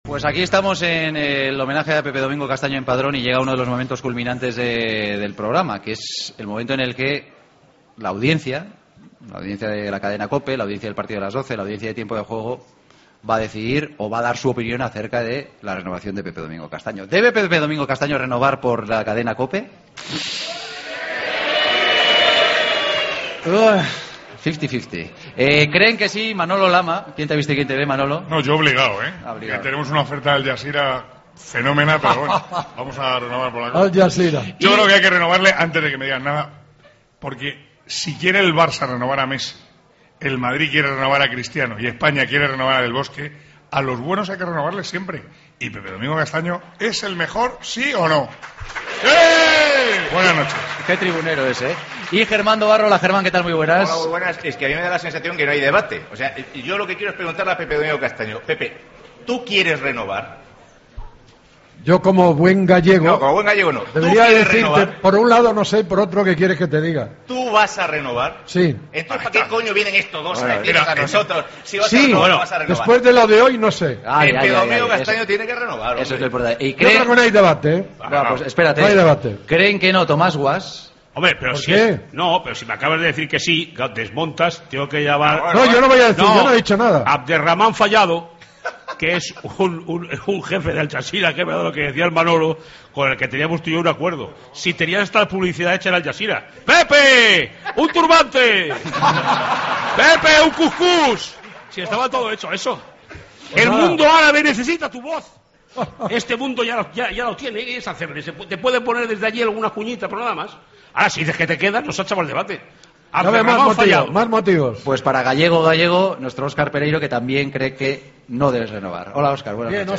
El debate de los jueves: ¿Debe renovar Pepe Domingo con la Cadena COPE?